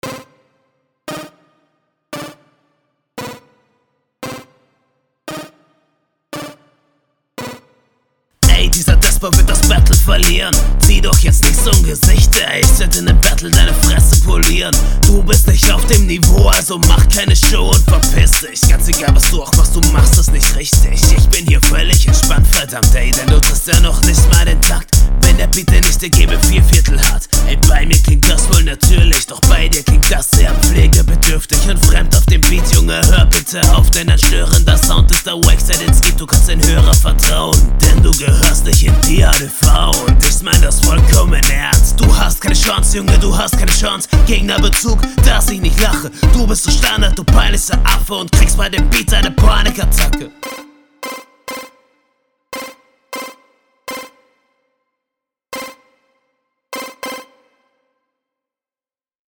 Flow: kann man machen.